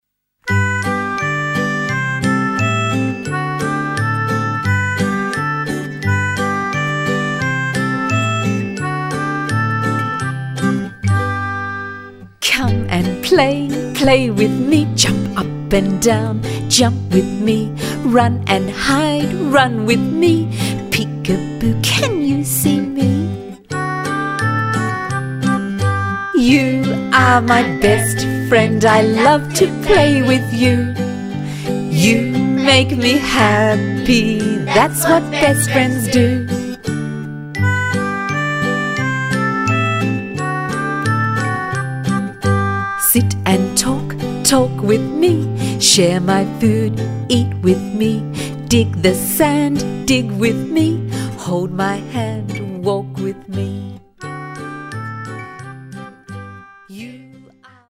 infants choir
children's music, Australian music